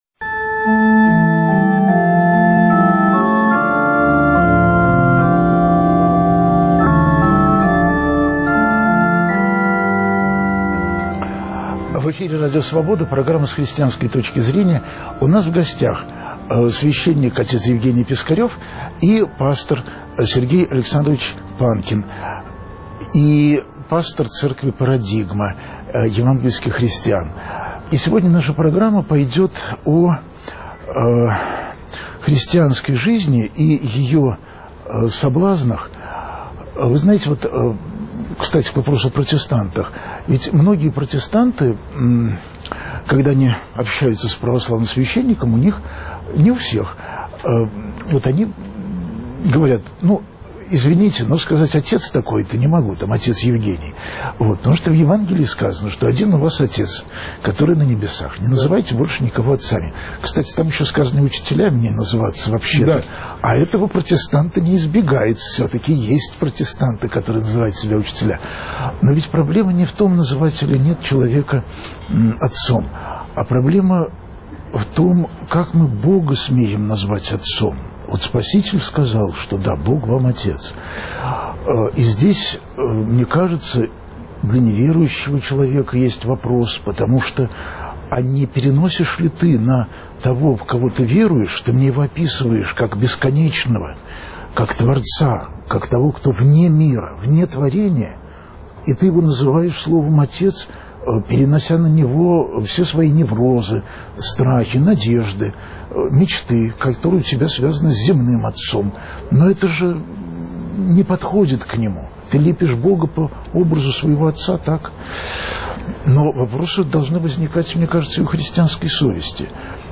слушают друг друга верующие и неверующие